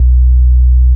CX_BASSS.WAV